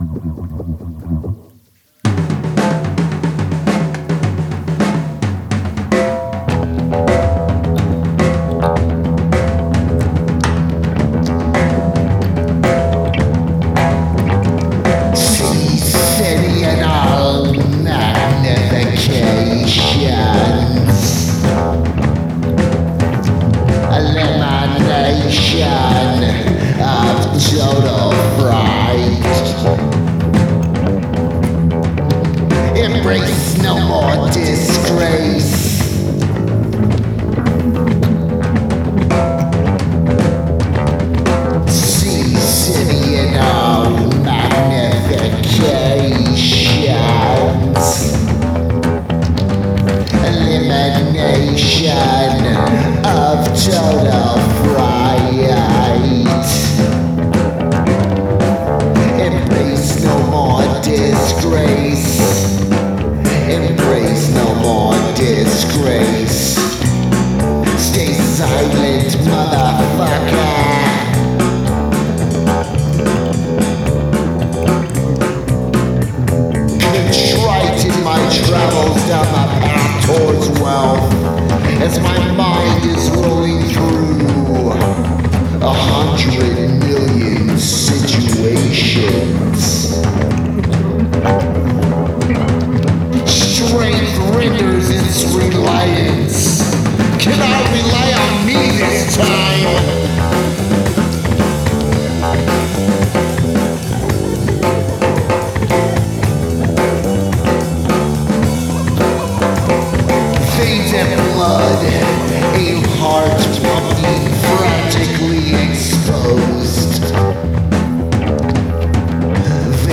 Percussion
Guitar
Bass